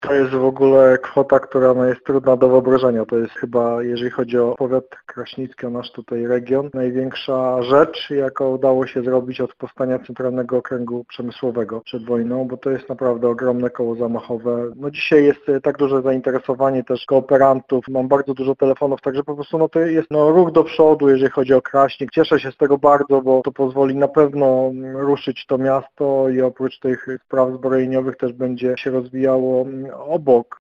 Jeżeli chodzi o powiat kraśnicki, nasz region, to jest chyba największa rzecz, jaką udało się zrobić od powstania Centralnego Okręgu Przemysłowego przed wojną – mówi w rozmowie z Radiem Lublin wicewojewoda lubelski Andrzej Maj.